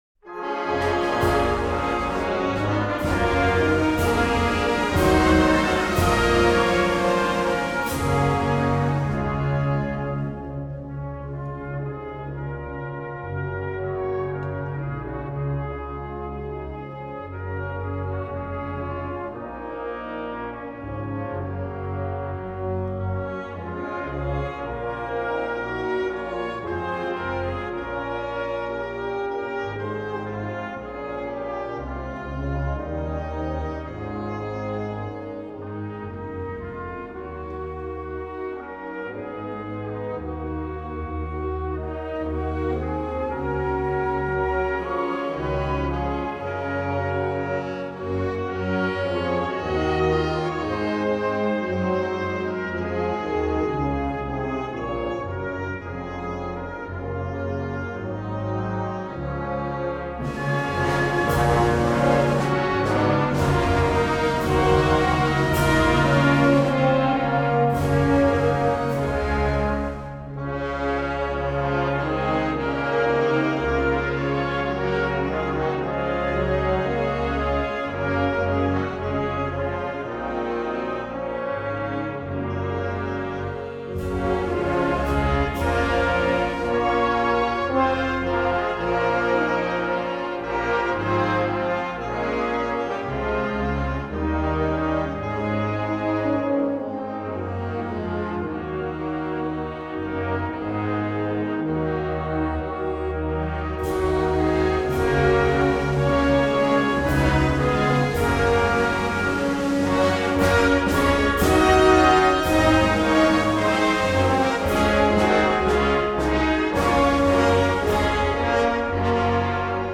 I couldn’t find the exact versions of the Hymns from the funeral so I have some different renditions by military bands, which are just as nice.